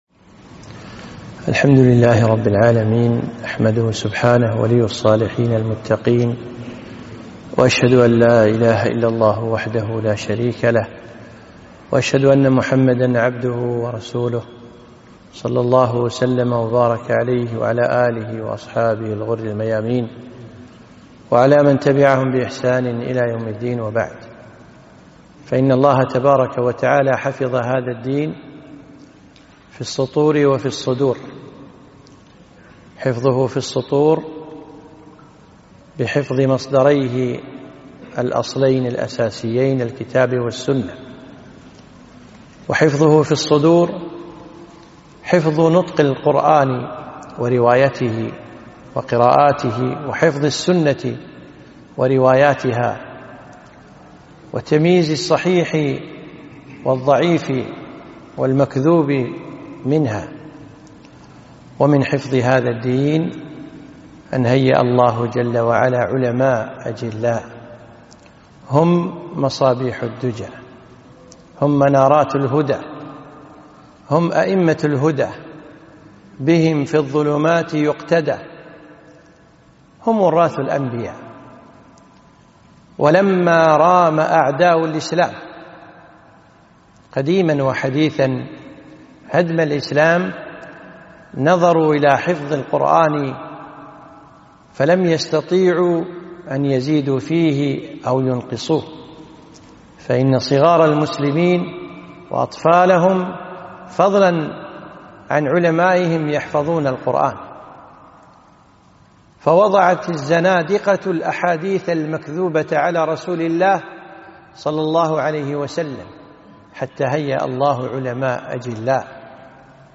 محاضرة - سيرة الإمام أبي حنيفة النعمان رضي الله عنه